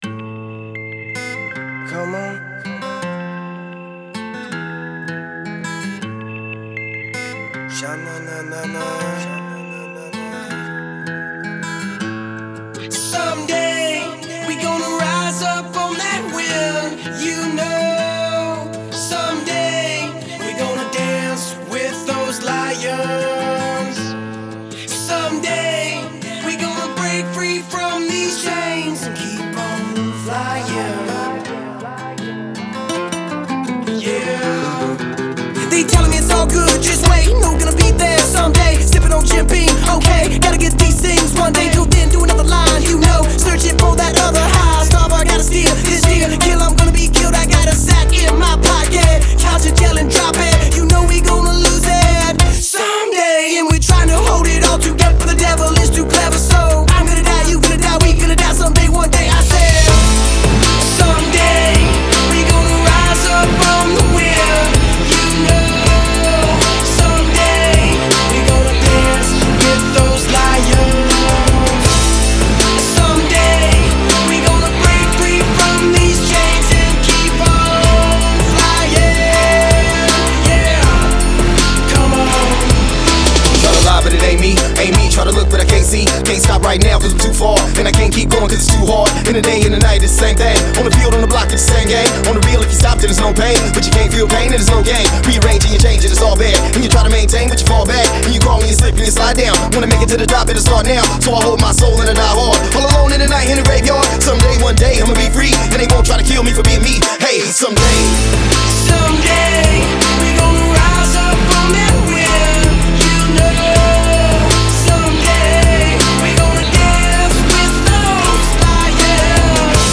Категория: Зарубежный рэп, хип-хоп